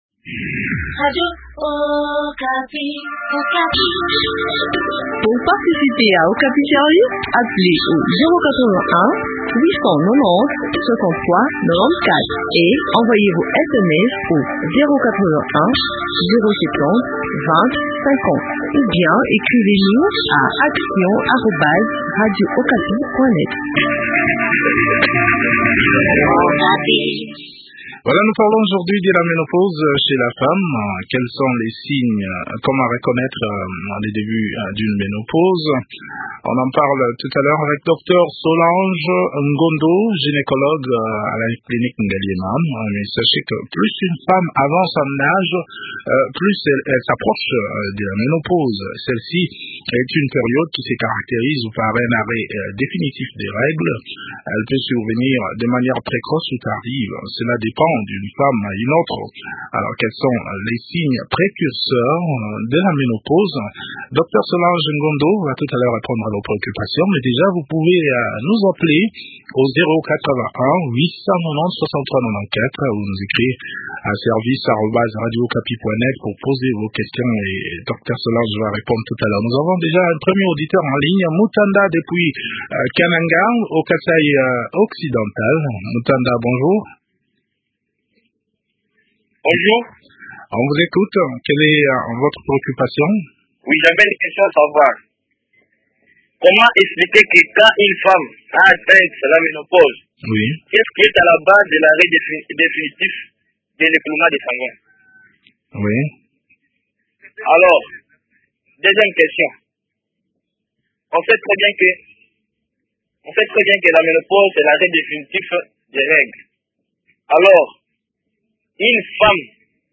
Quels sont alors les signes précurseurs de la ménopause ? Le point de ce changement physiologique chez la femme dans cet entretien